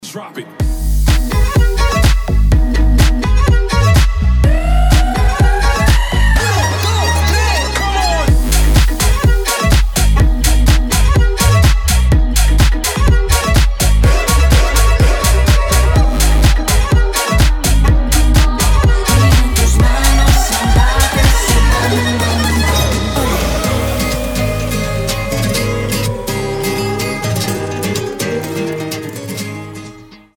гитара
скрипка
Brazilian bass
испанские
Neoclassical
Фламенко